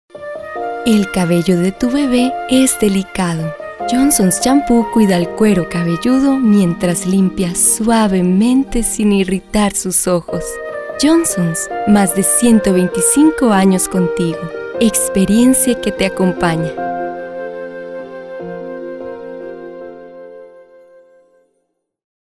Dinamic voice, character voices, woman, child
Sprechprobe: Werbung (Muttersprache):